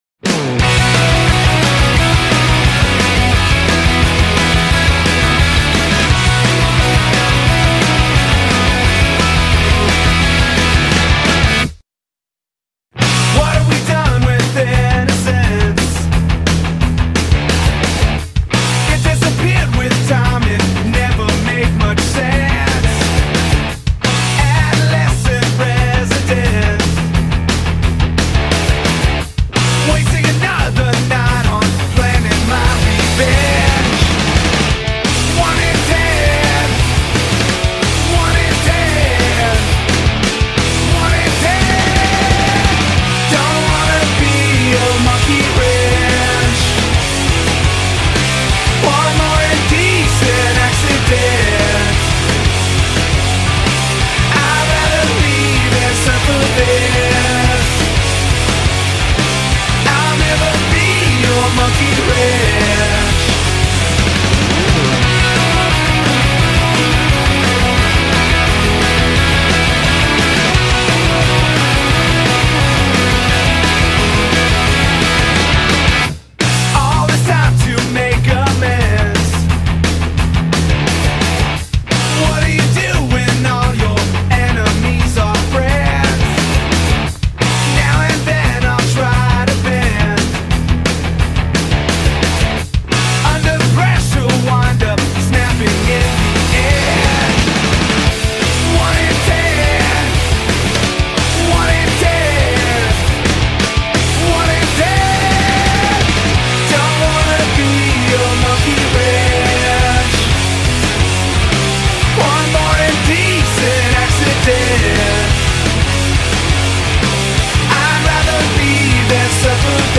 Genero: Grunge
Calidad: Stereo (Exelente)(Remasterizado)(Recontruido)